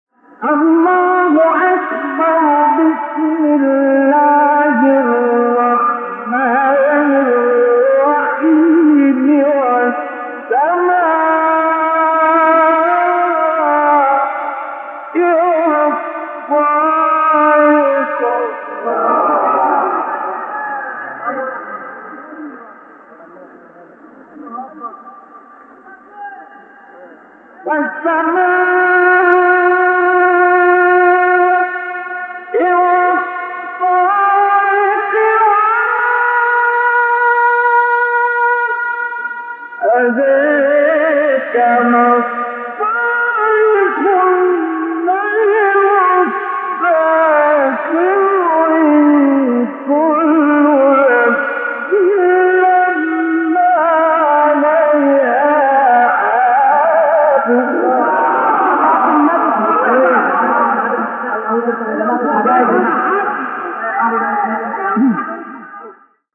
آیه 1-4 سوره طارق استاد مصطفی اسماعیل | نغمات قرآن | دانلود تلاوت قرآن